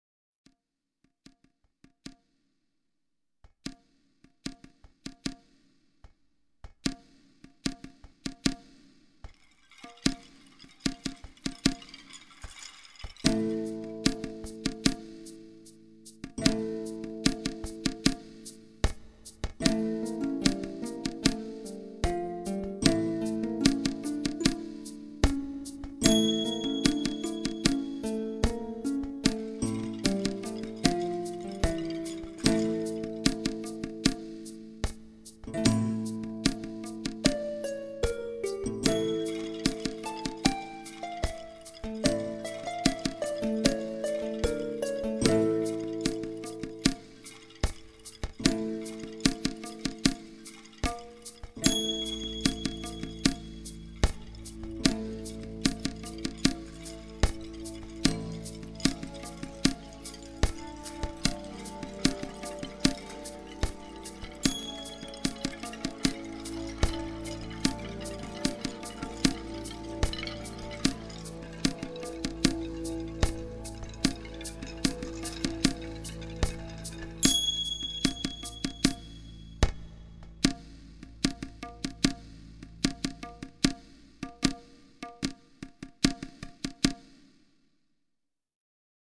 В наше время создается огромное количество псевдо-египетской музыки.